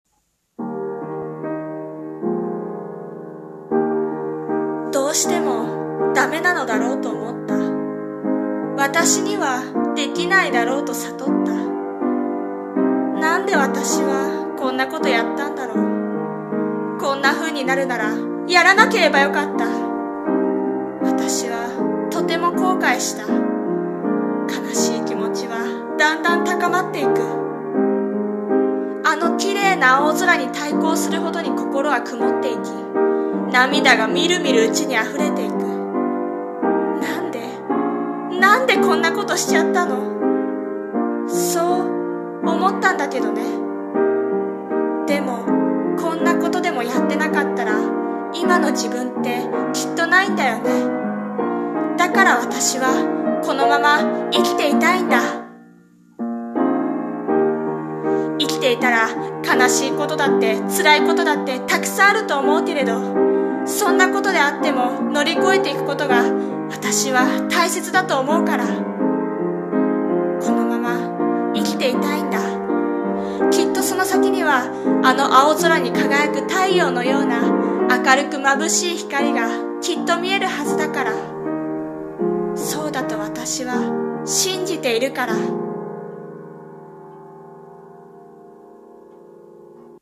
さんの投稿した曲一覧 を表示 生きていたくて 【声劇台本】【朗読台本】